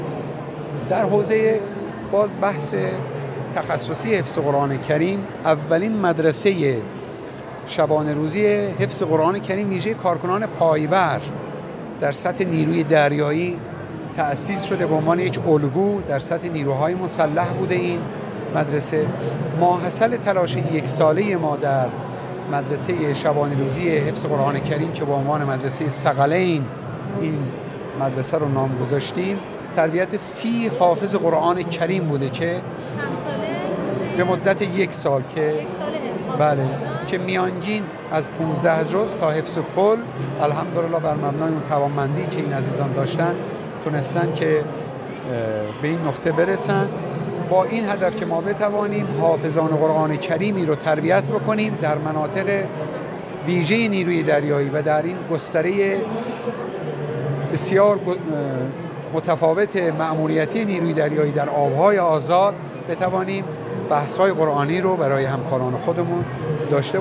در گفت‌و‌گو با خبرنگار ایکنا